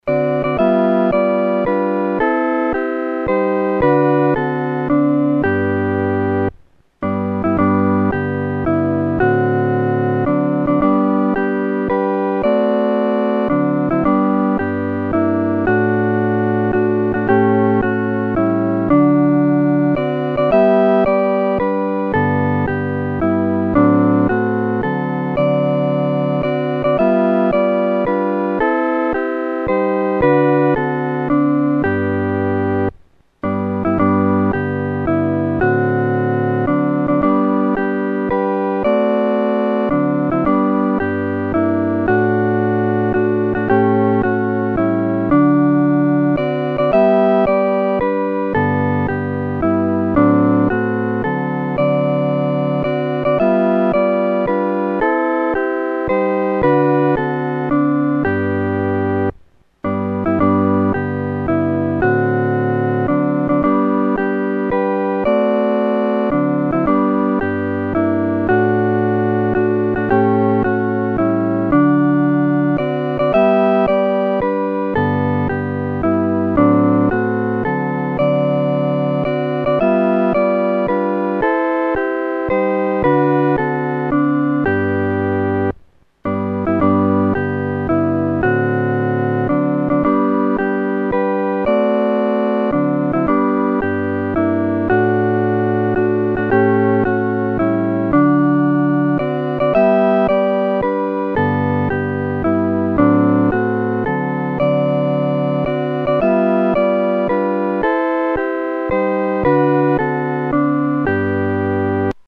合奏（四声部）